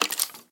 脚步声
骷髅行走时随机播放这些音效
Minecraft_Skeleton_skeleton_step4.mp3